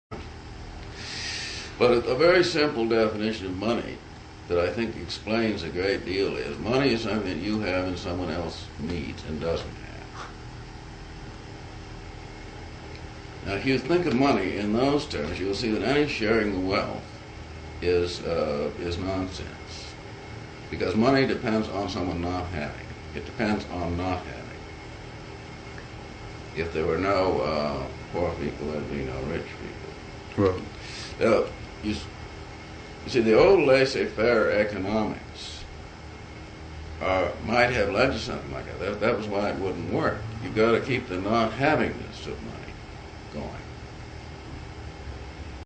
The whole interview: